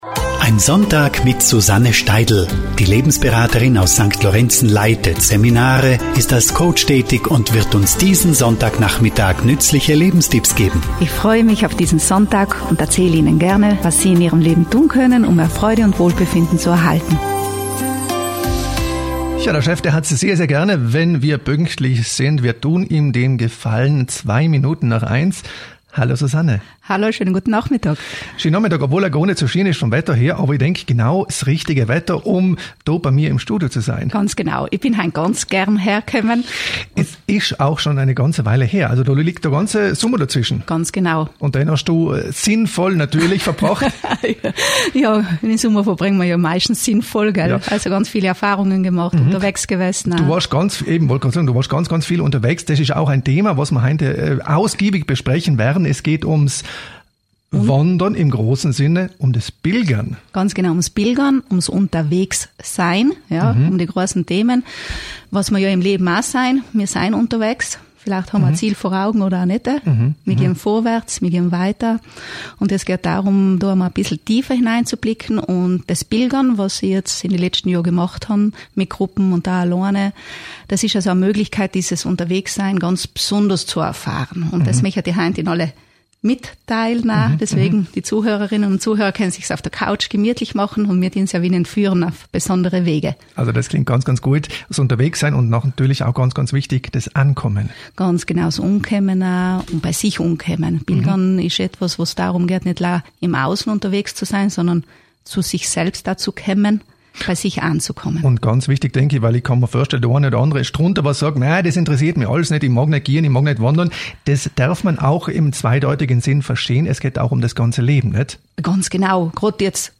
In dieser Radiosendung habe ich über das Pilgern gesprochen – eine Möglichkeit seinem Selbst näher zu kommen.
Radiosendung-Pilgern.mp3